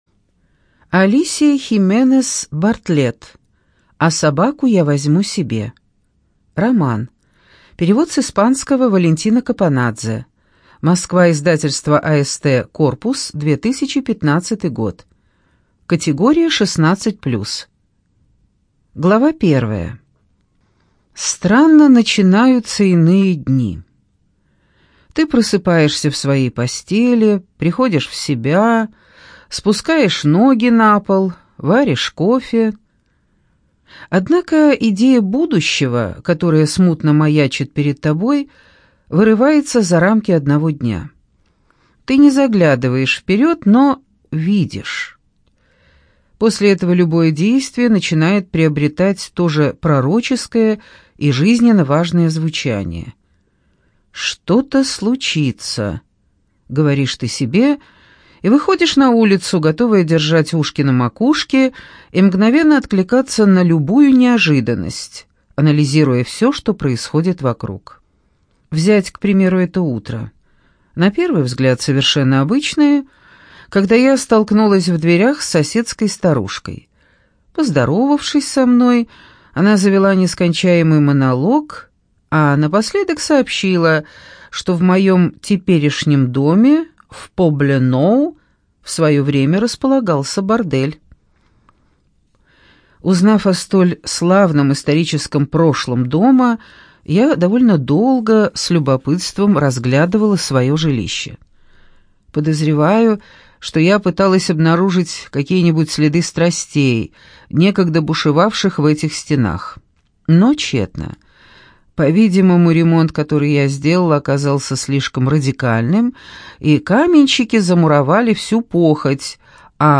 ЖанрДетективы и триллеры
Студия звукозаписиЛогосвос